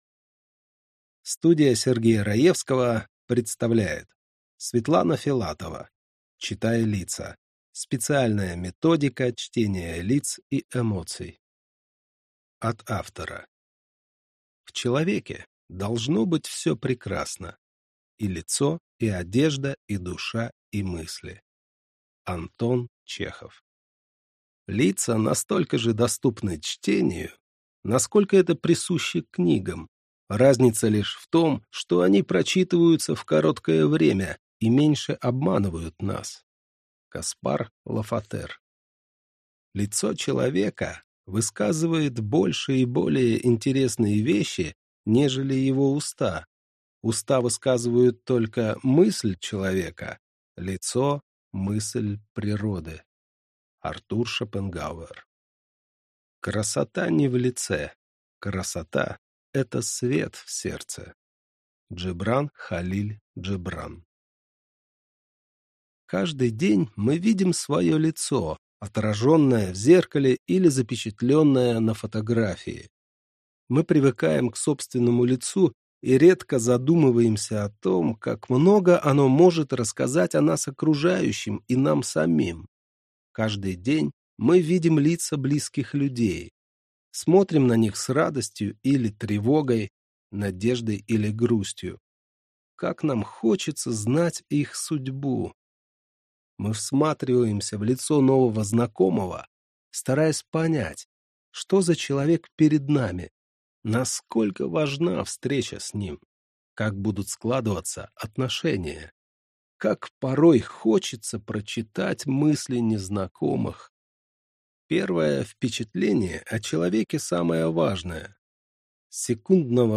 Аудиокнига Читай лица! Специальная методика чтения лиц и эмоций | Библиотека аудиокниг